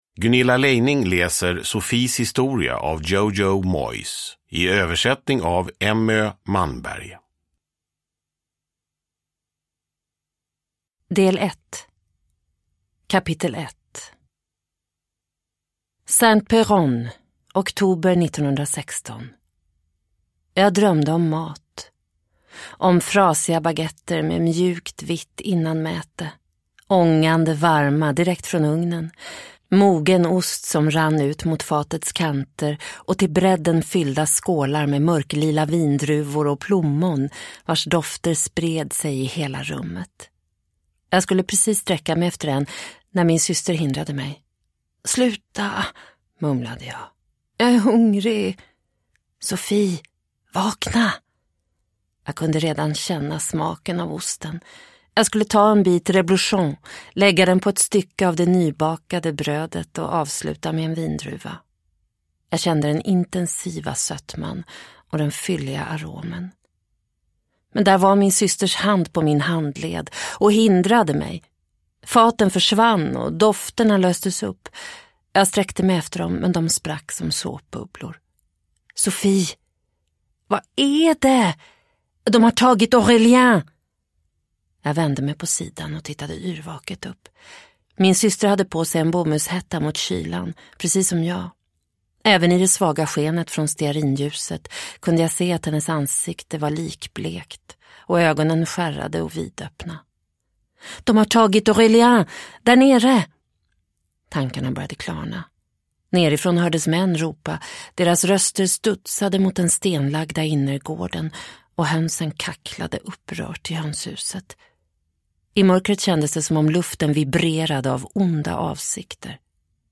Sophies historia – Ljudbok – Laddas ner